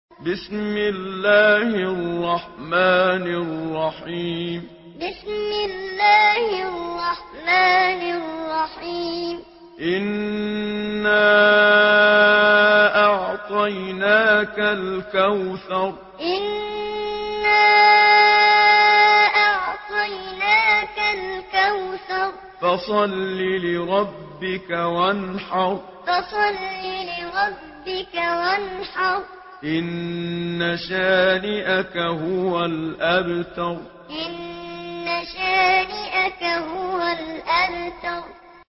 سورة الكوثر MP3 بصوت محمد صديق المنشاوي معلم برواية حفص